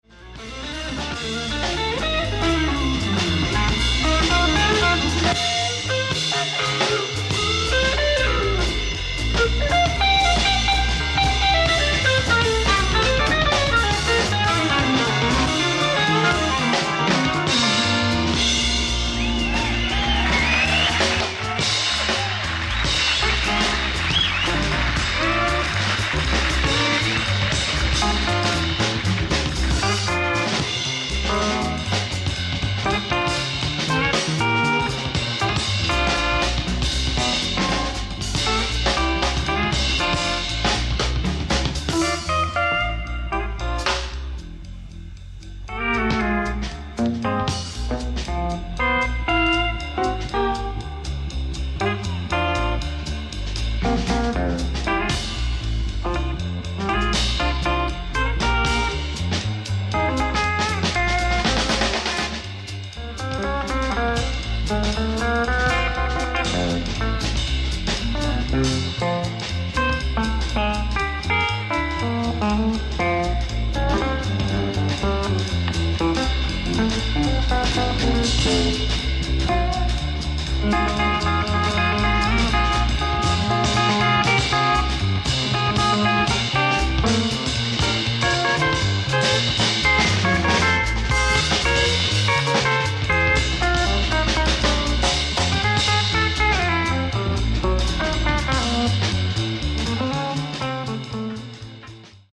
ライブ・アット・カフェ・モンマルトル、コペンハーゲン、スウェーデン 06/07/1988
初登場ステレオ・サウンドボード！！
※試聴用に実際より音質を落としています。